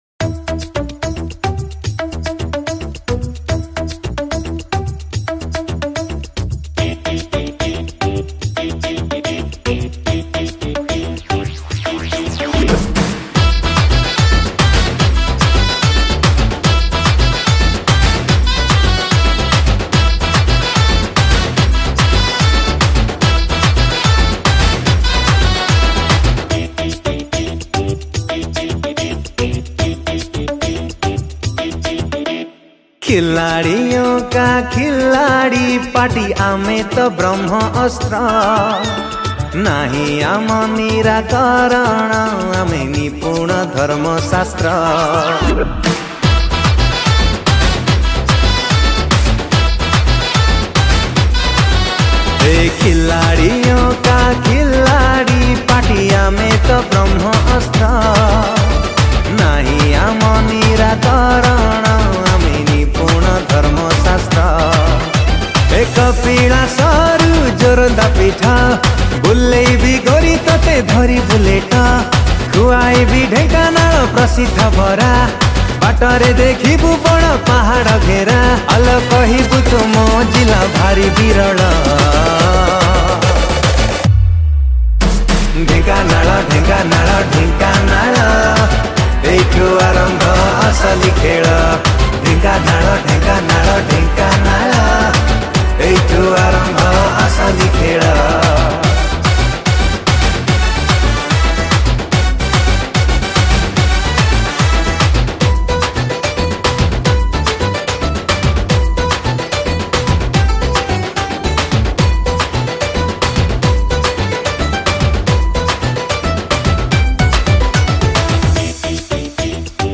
Recorded At : MRR Studio,Cuttack